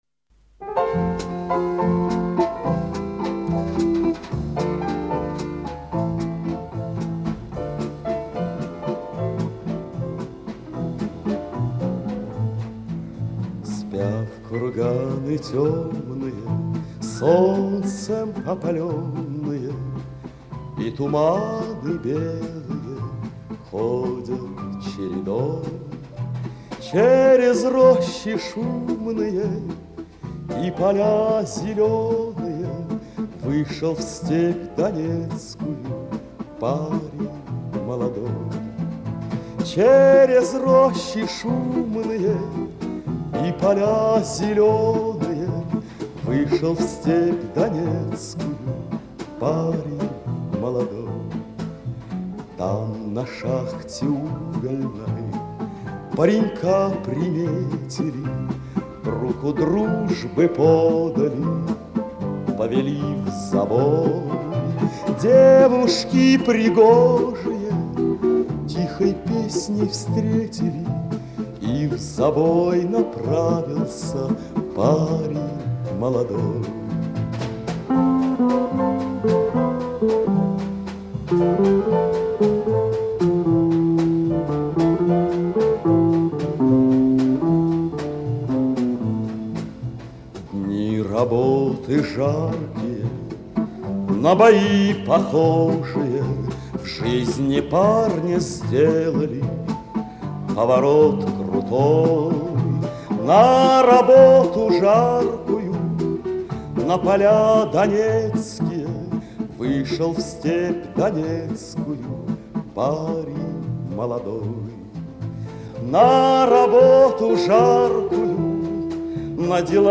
Источник фонотека Пензенского Дома радио